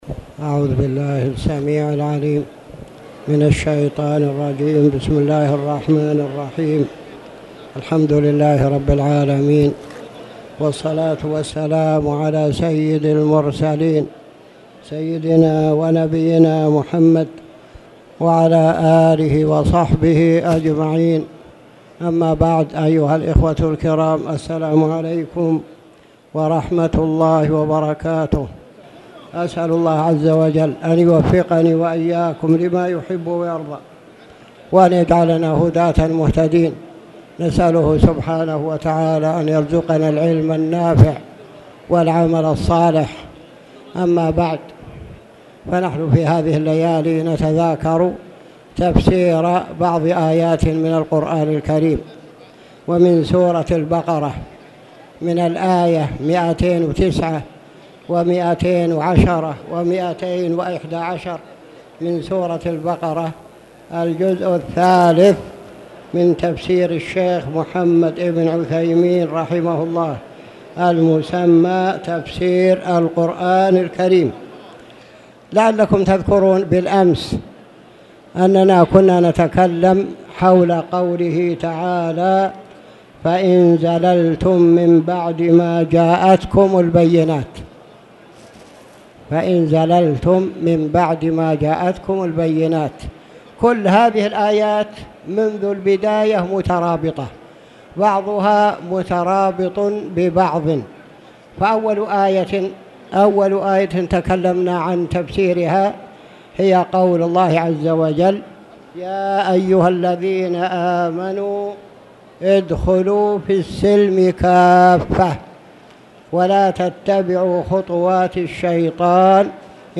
تاريخ النشر ٢ رمضان ١٤٣٧ هـ المكان: المسجد الحرام الشيخ